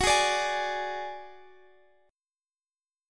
Listen to Gbsus2#5 strummed